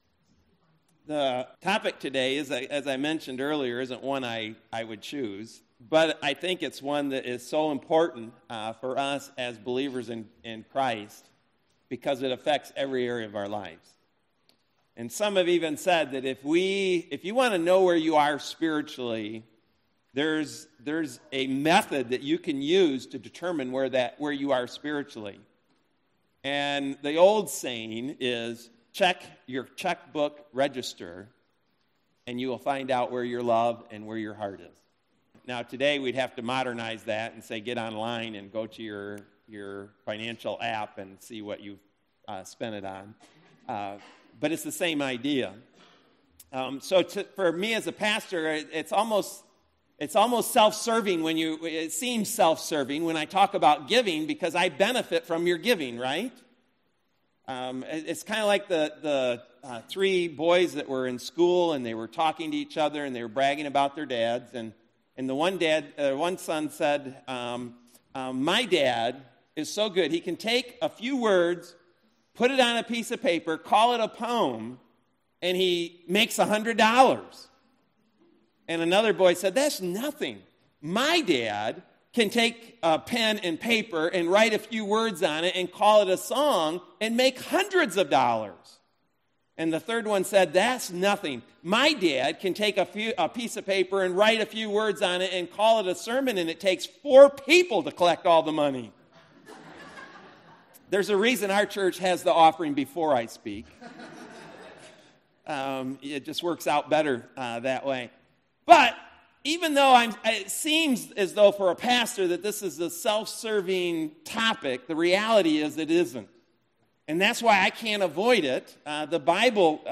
MP3 SUBSCRIBE on iTunes(Podcast) Notes Discussion Sermons in this Series October 21, 2018 Loading Discusson...